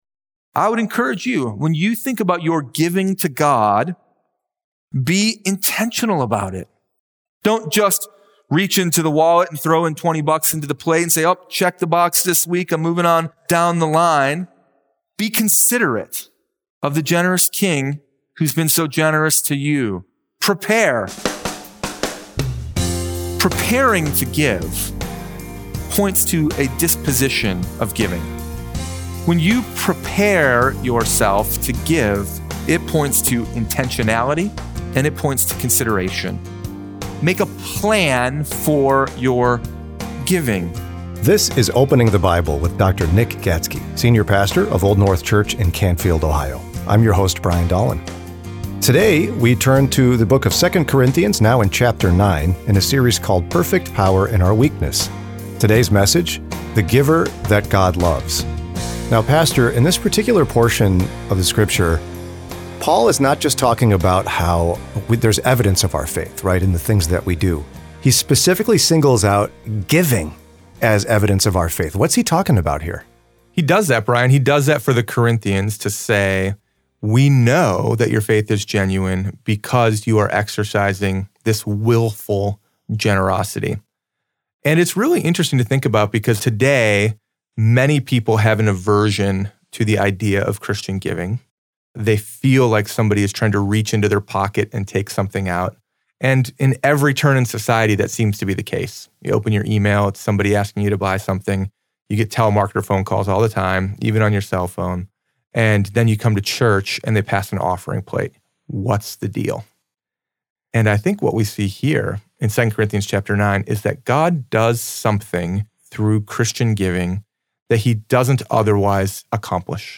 Sermon Library – Old North Church